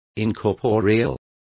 Complete with pronunciation of the translation of incorporeal.